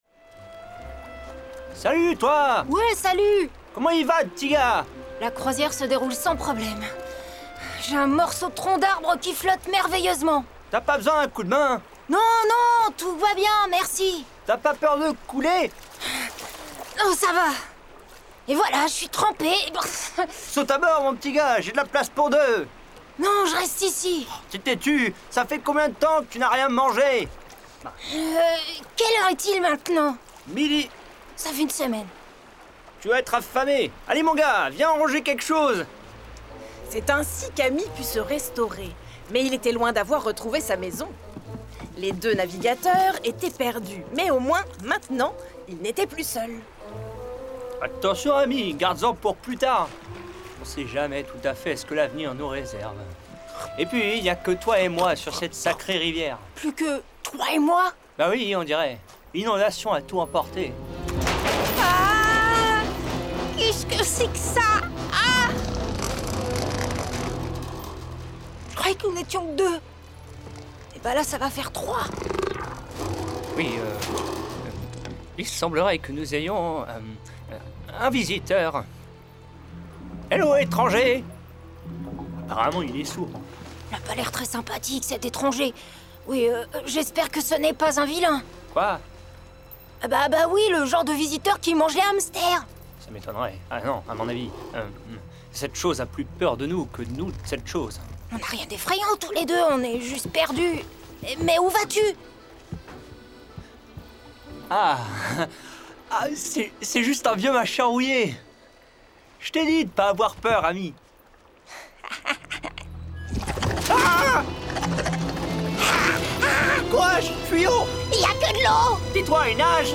Voix off
35 - 50 ans - Mezzo-soprano